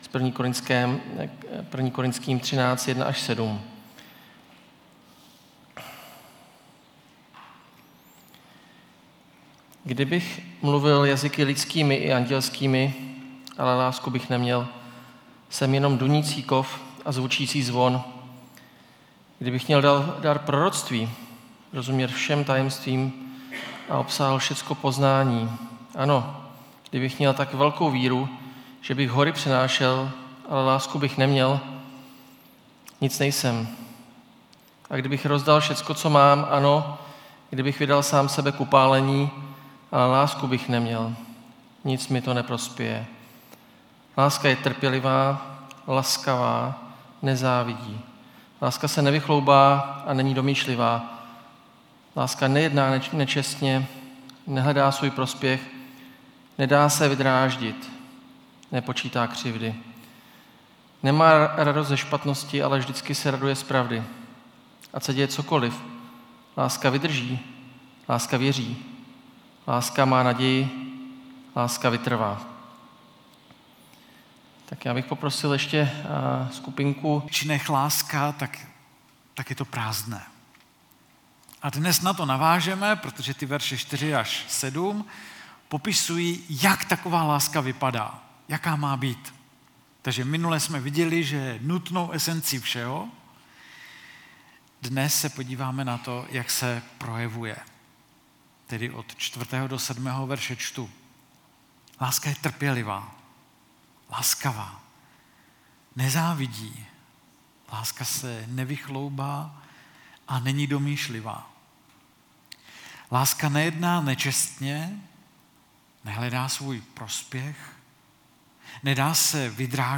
Nedělní bohoslužby